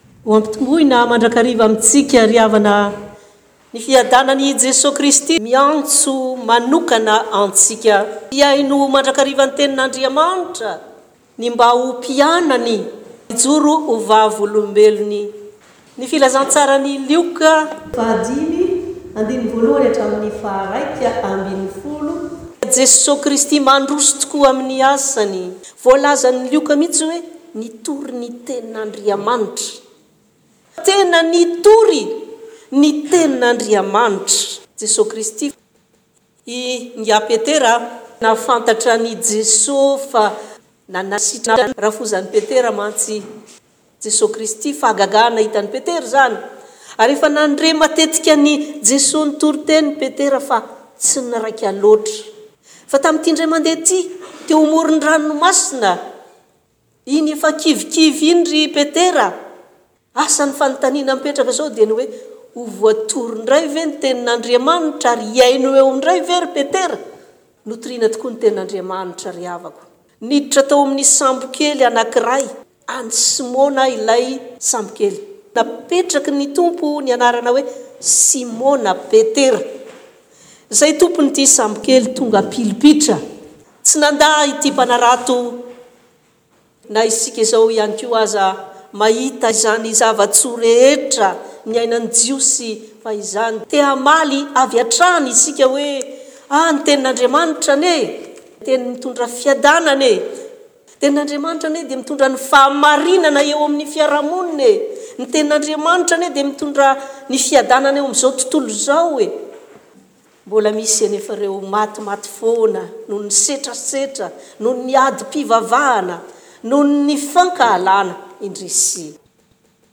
Toriteny – Prédications